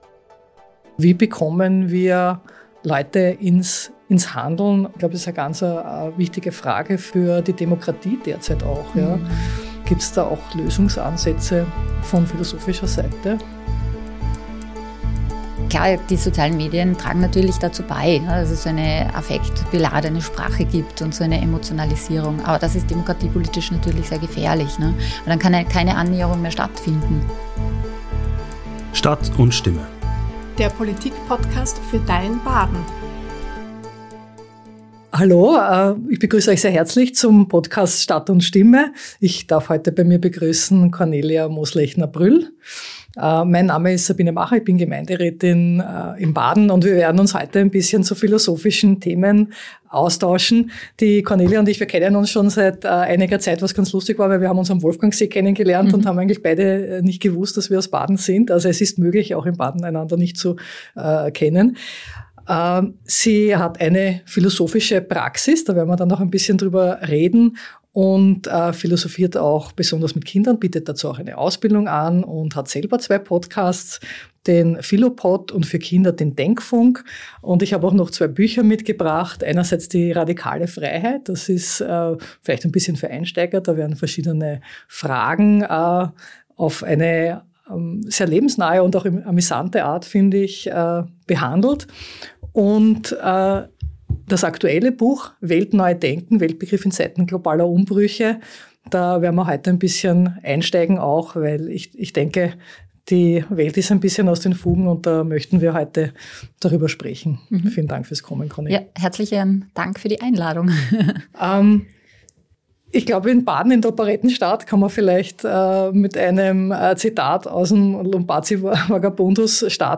Ein tiefgründiges und hoffnungsvolles Gespräch über Philosophie, Verantwortung und Gesellschaftsveränderung.